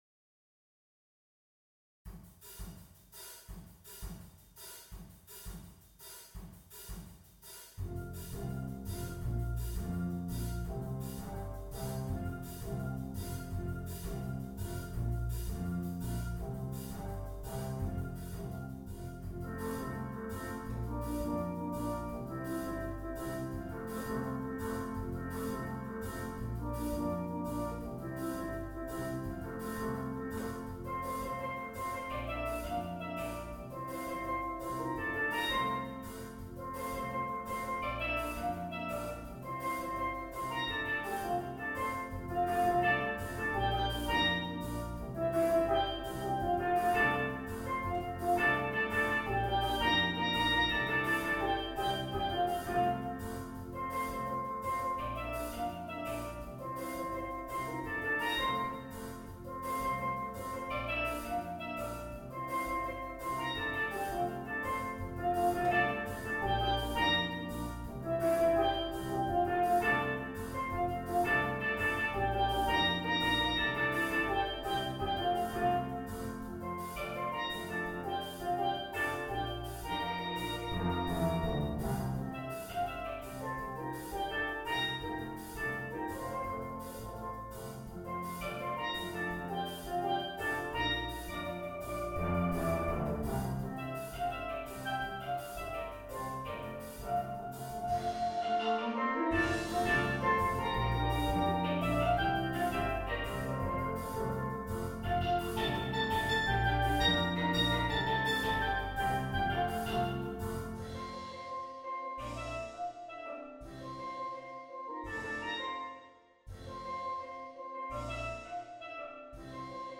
Instrumentation: steel drum